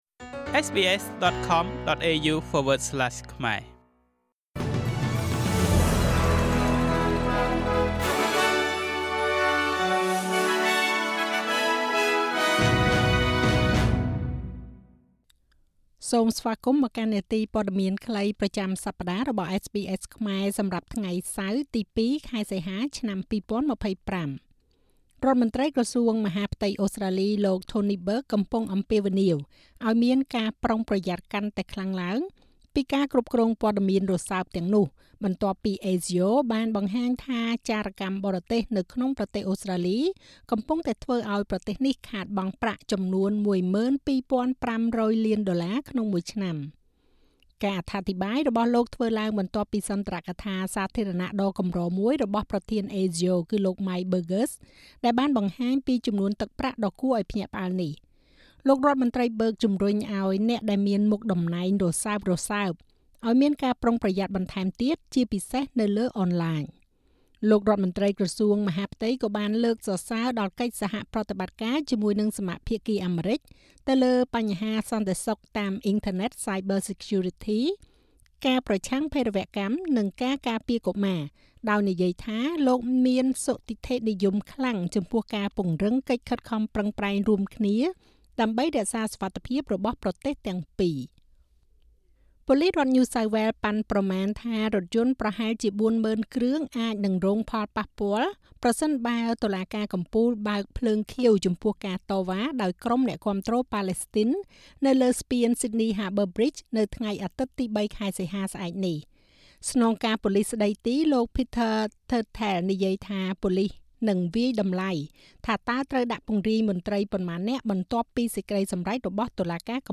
នាទីព័ត៌មានខ្លីប្រចាំសប្តាហ៍របស់SBSខ្មែរ សម្រាប់ថ្ងៃសៅរ៍ ទី២ ខែសីហា ឆ្នាំ២០២៥